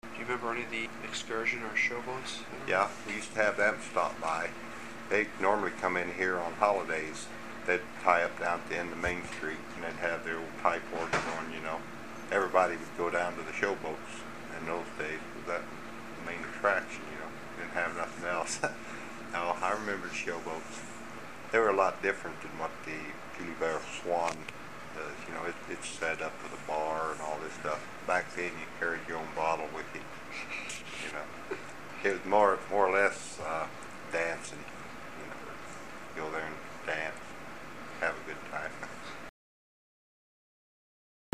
HTR Oral History, 08/18/1